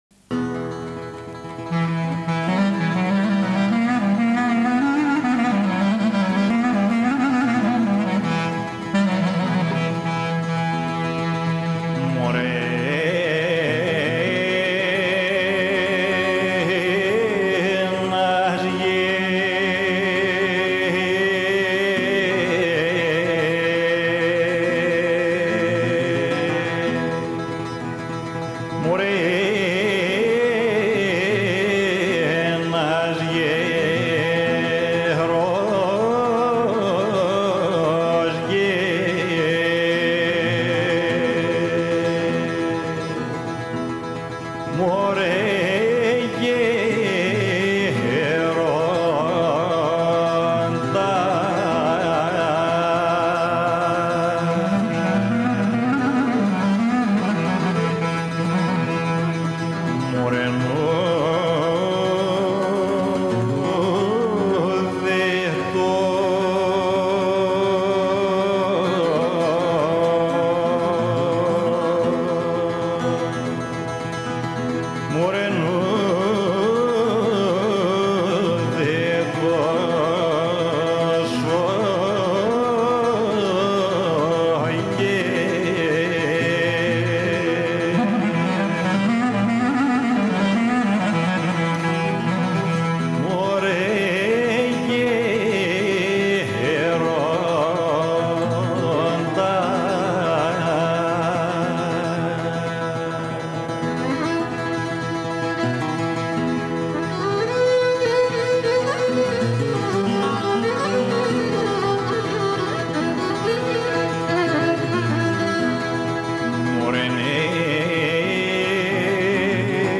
Το τραγουδάκι που παραθέτω είναι ενδεικτικό: